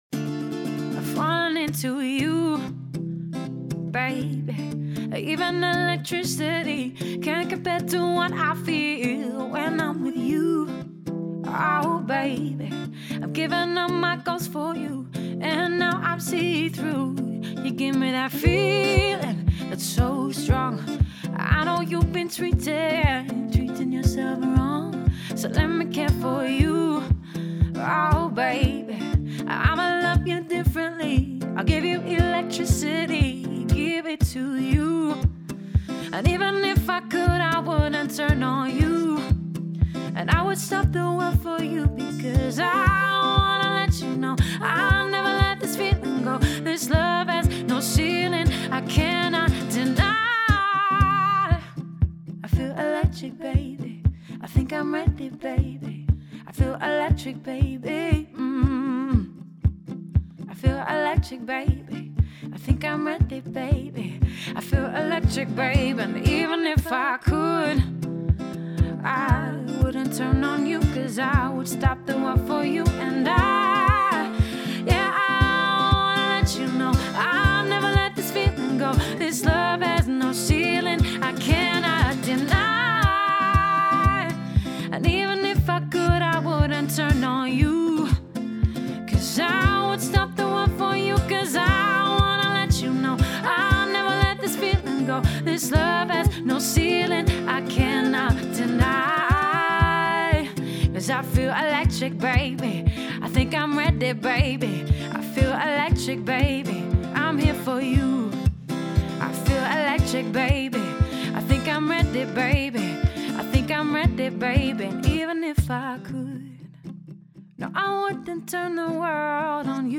Vocals | Guitar | Looping
One woman, one guitar, one incredible voice.